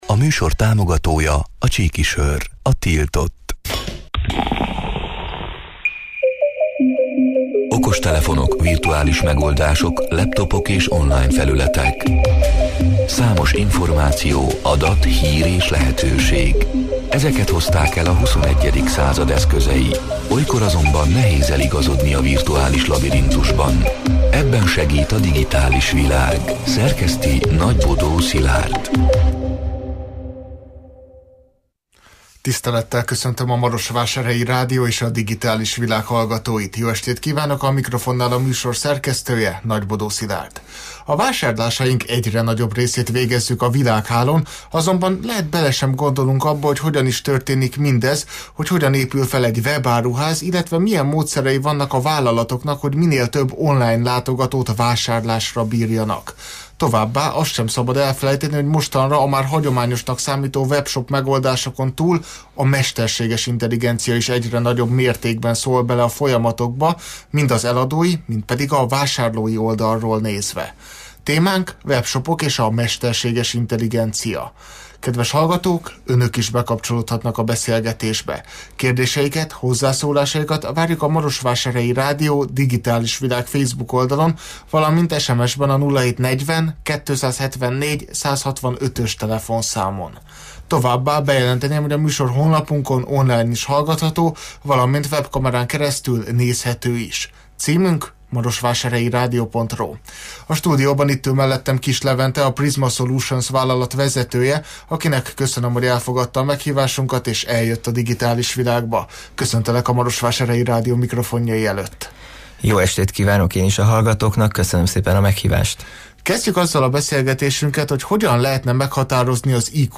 A Marosvásárhelyi Rádió Digitális Világ (elhangzott: 2025. május 6-án, kedden este órától élőben) c. műsorának hanganyaga: